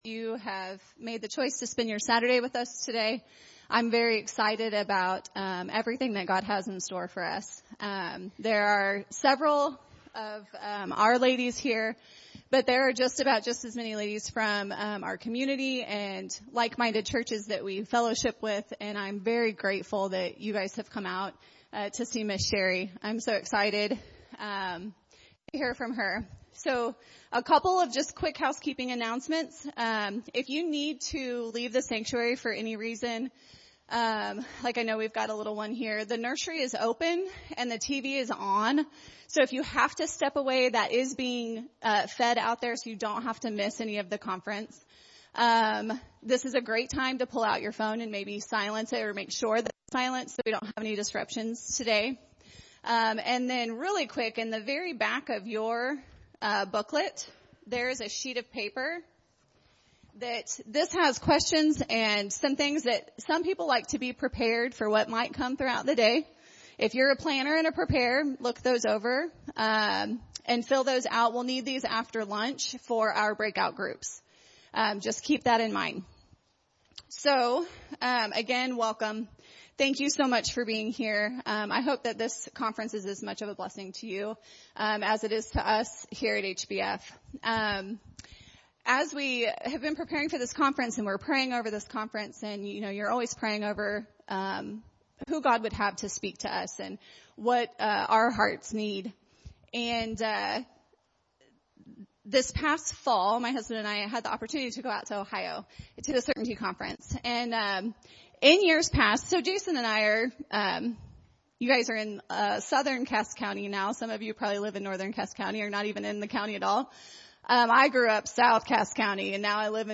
Woman's Conference | Heartland Baptist Fellowship